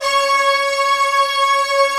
Index of /90_sSampleCDs/Optical Media International - Sonic Images Library/SI1_Fast Strings/SI1_Fast octave